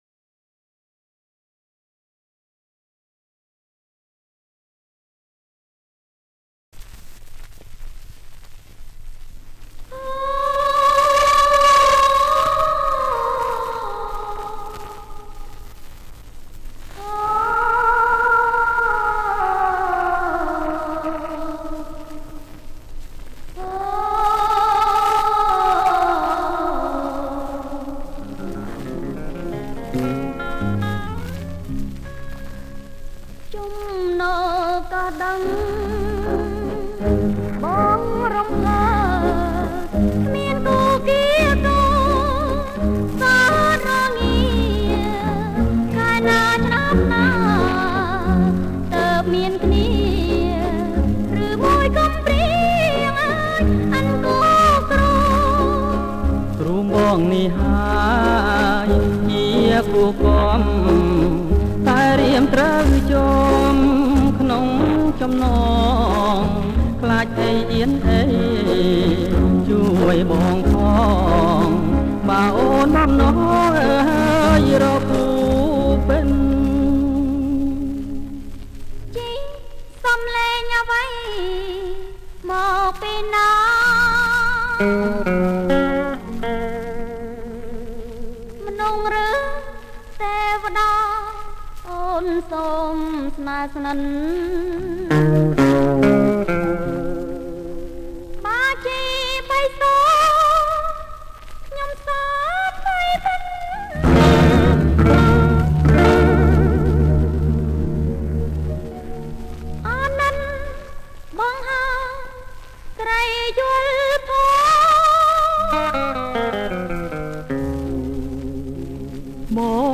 • ប្រគំជាចង្វាក់ Bolero lent